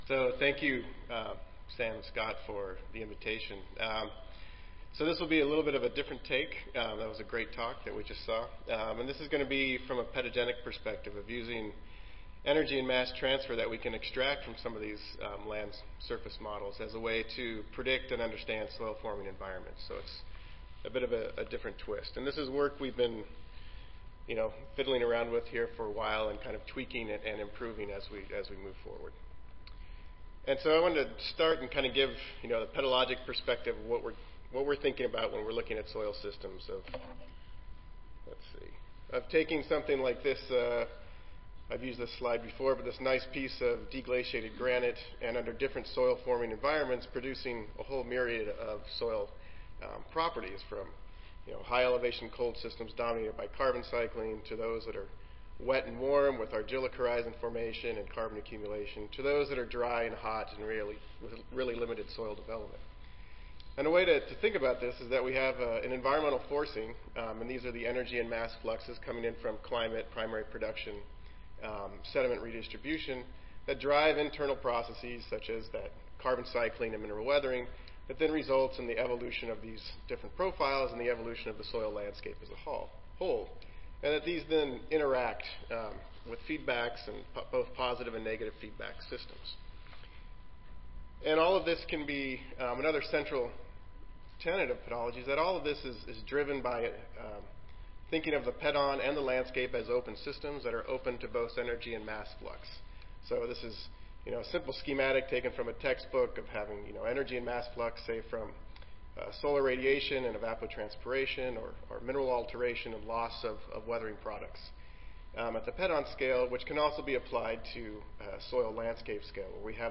Oral Session
Recorded Presentation